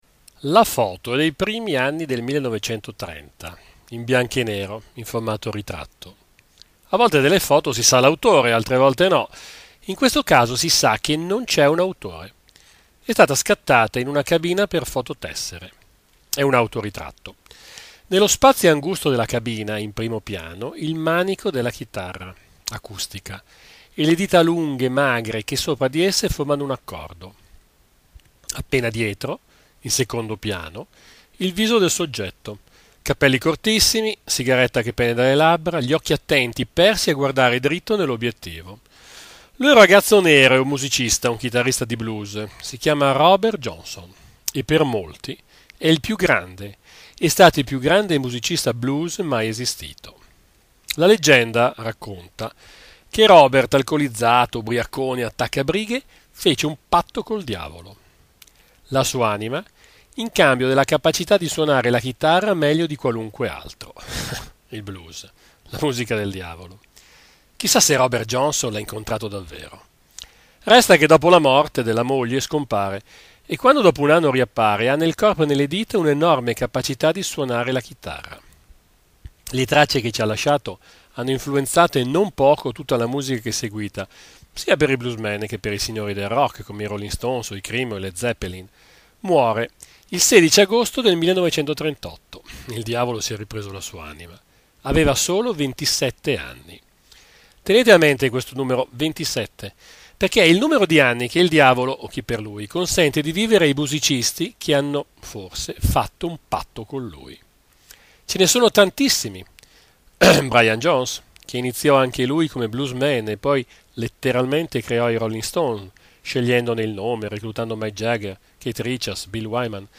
Puntate della rubrica radiofonica l'Angolo di Orso Curioso trasmessa da Web Pieve Radio